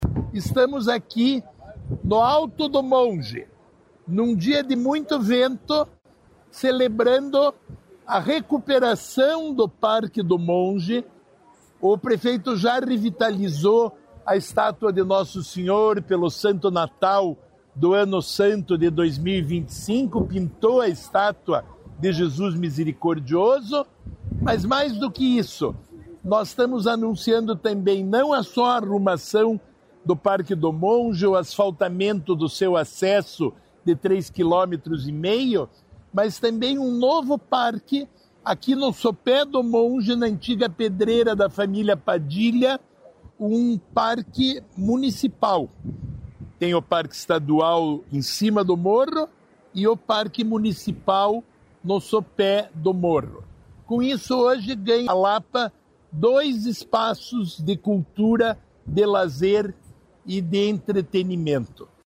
Sonora do secretário estadual do Desenvolvimento Sustentável, Rafael Greca, sobre ampliar áreas de preservação e fortalecer o turismo na Lapa